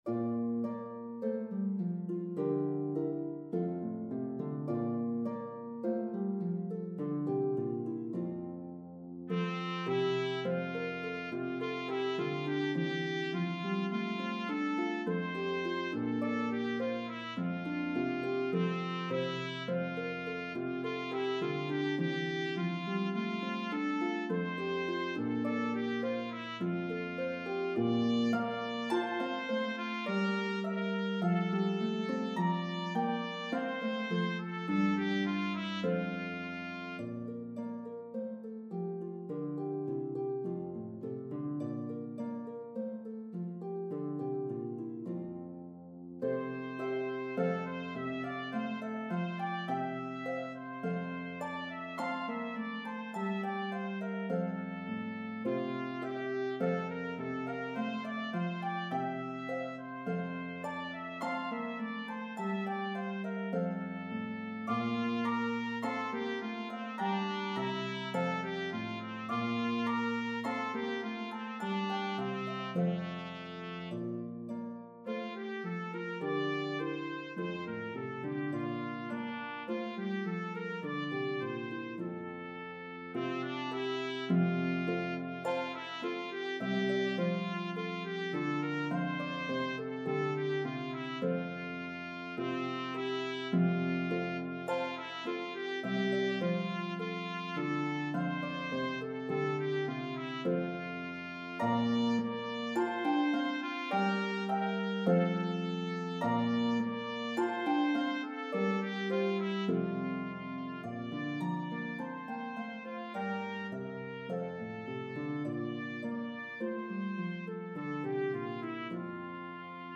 This arrangement has 4 verses separated by interludes.